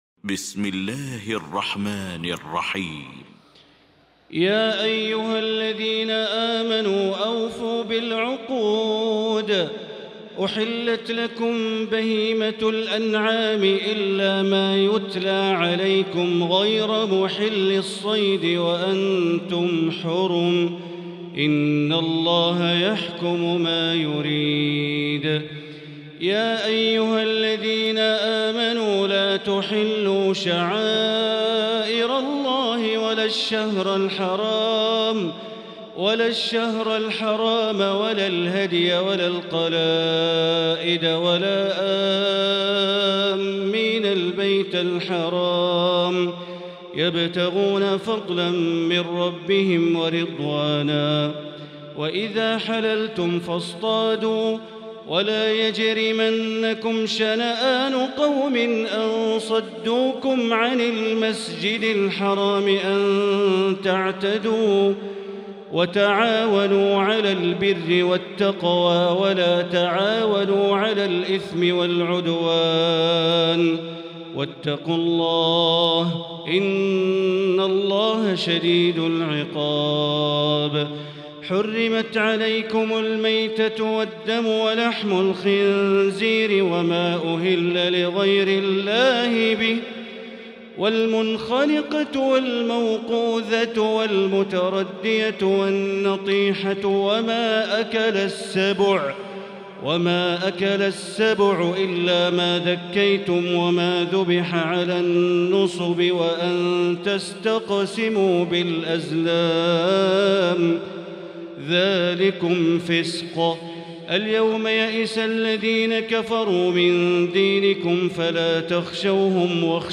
المكان: المسجد الحرام الشيخ: معالي الشيخ أ.د. بندر بليلة معالي الشيخ أ.د. بندر بليلة فضيلة الشيخ عبدالله الجهني فضيلة الشيخ ياسر الدوسري المائدة